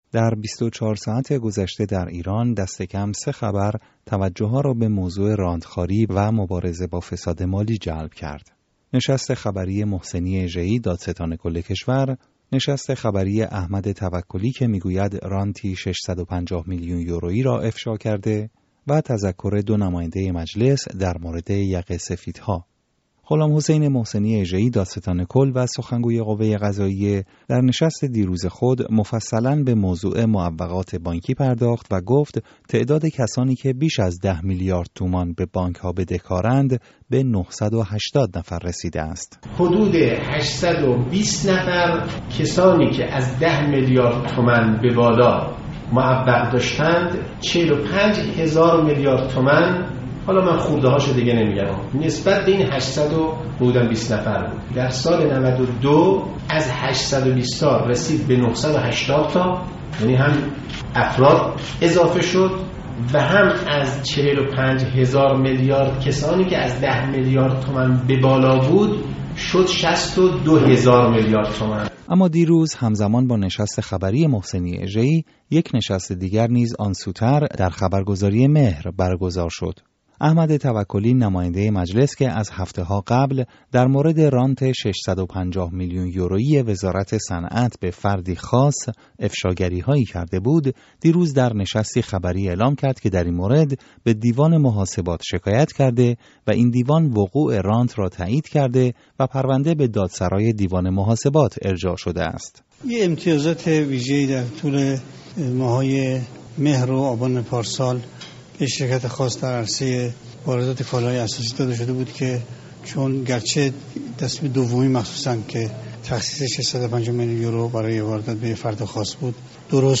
گزارش رادیو فردا در مورد «مفسدان اقتصادی» را بشنوید